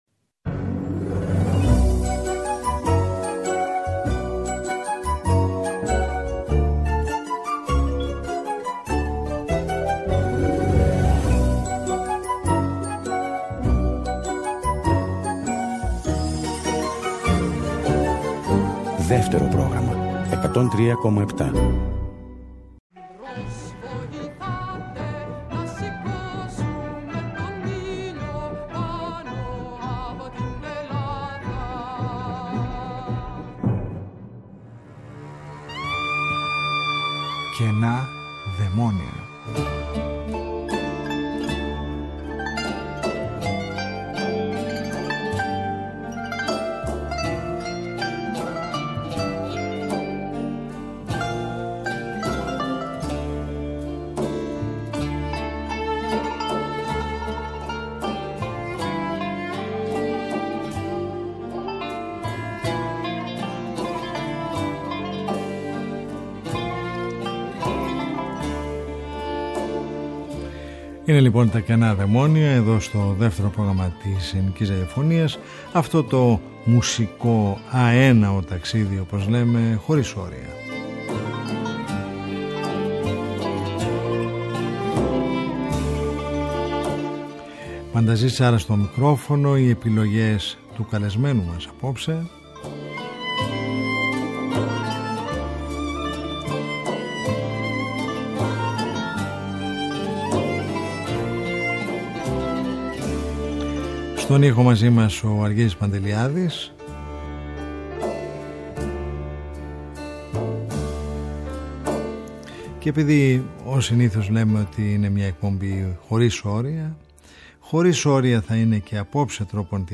ηχογραφήθηκε στο Studio B Της Ελληνικής Ραδιοφωνίας